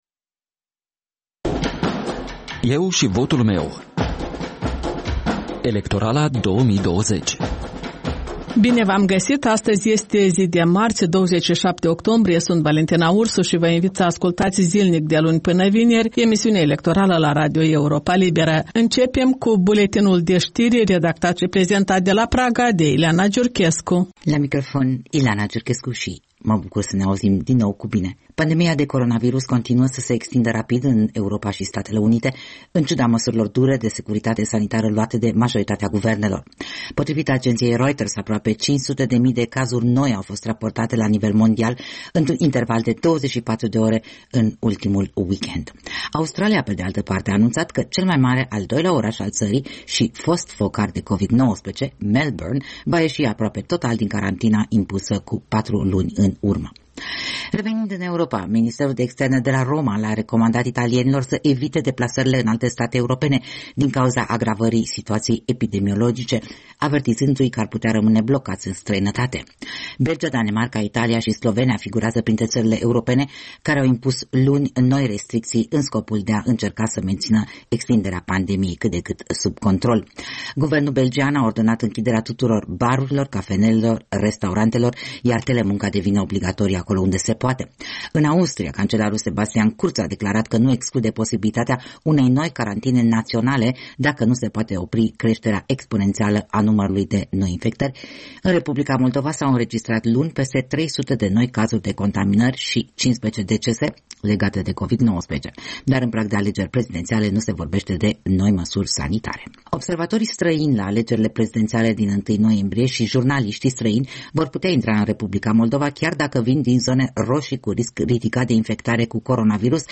De luni până vineri, de la ora 13.00, radio Europa Liberă prezintă interviuri cu toți candidații în alegerile prezidențiale din 1 noiembrie, discuții cu analiști și formatori de opinie, vocea străzii și cea a diasporei.